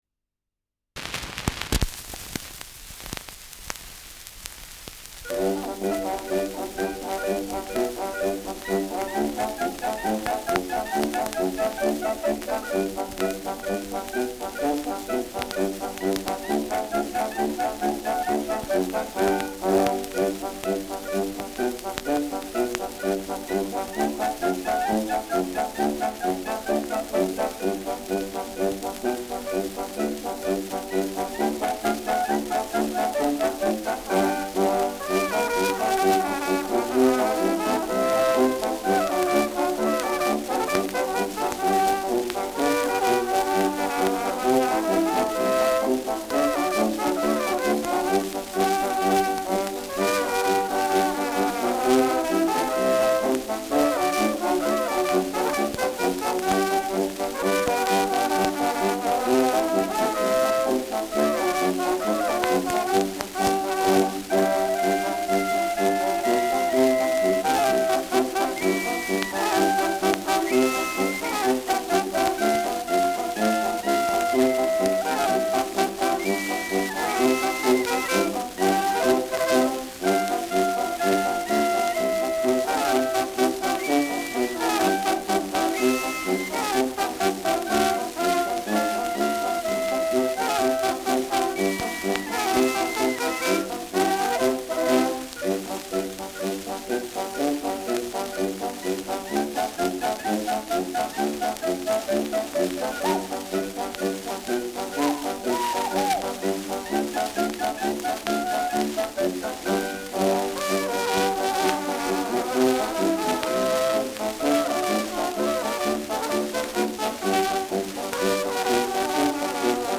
Schellackplatte
Starkes Grundrauschen : Gelegentlich leichtes bis stärkeres Knacken